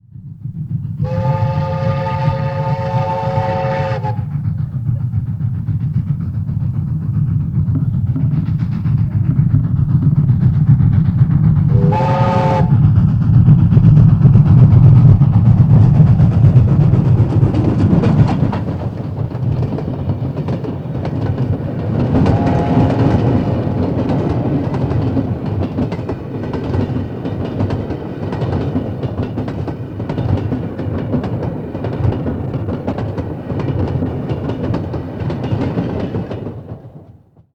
Звуки поезда
Поезд надвигается издали и подает сигнал